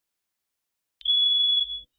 Car Horn
# car # horn # honk About this sound Car Horn is a free sfx sound effect available for download in MP3 format.
354_car_horn.mp3